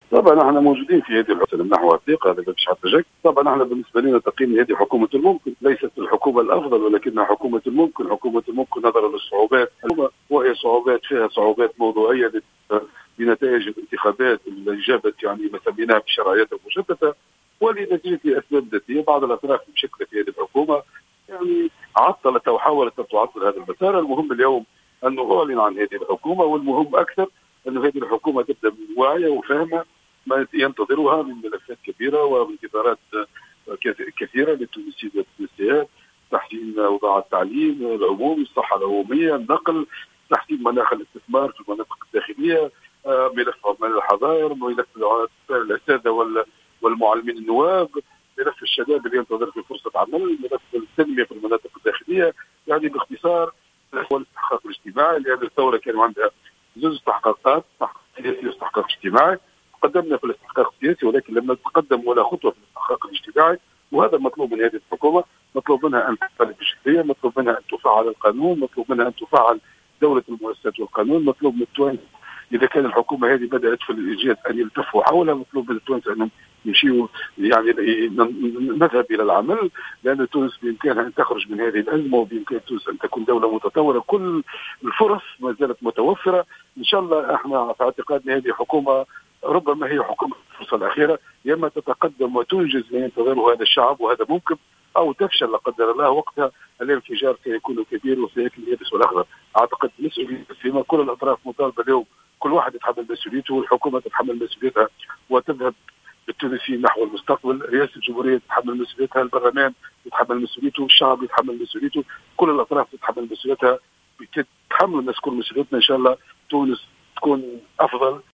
أكد الأمين العام لحركة الشعب زهير المغزاوي في تصريح للجوهرة "اف ام" أن الحركة ستمنح الثقة لحكومة إلياس الفخفاخ لأنها مشاركة فيها رغم أنها ليست الحكومة الأفضل ولكنها حكومة الممكن وفق تعبيره.